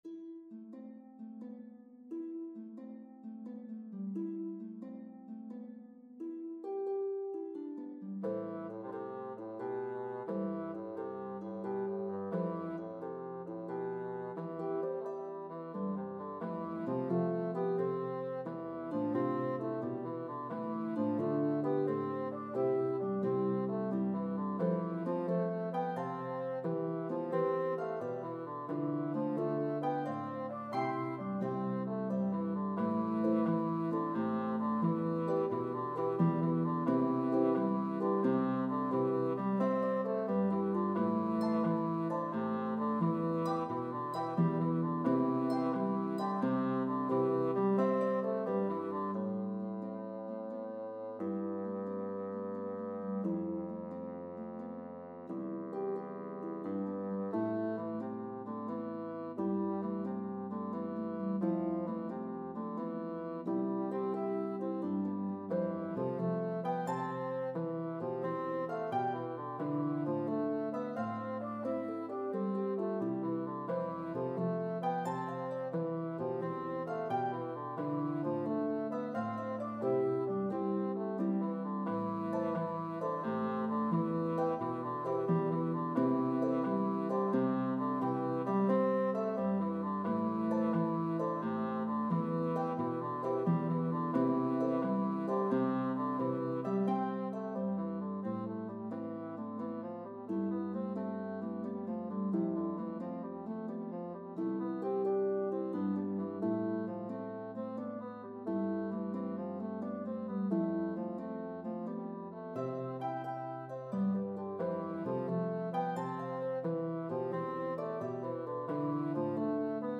This entertaining 5+ minute medley begins sweetly
slip jig in 9/8
reel in 4/4
jig in 6/8
The melodies are shared between instruments.